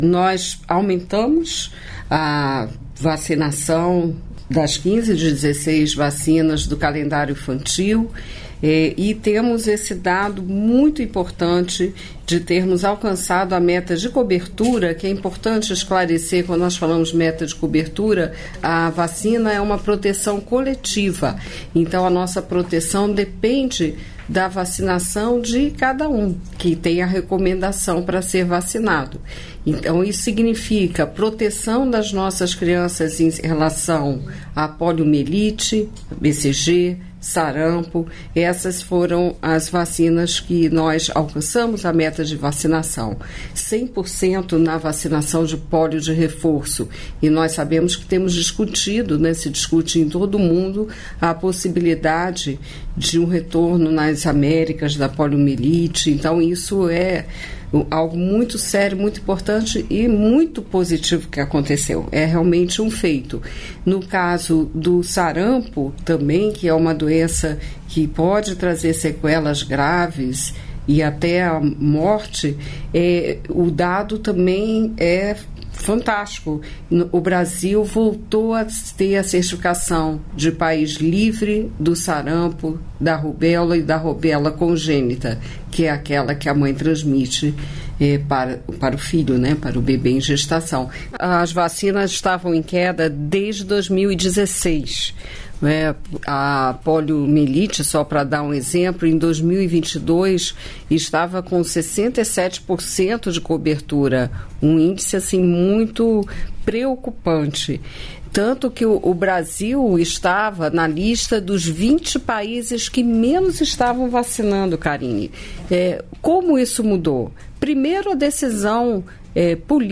Trecho da participação da ministra da Saúde, Nísia Trindade, no programa "Bom Dia, Ministra" desta quinta-feira (12), nos estúdios da EBC, em Brasília.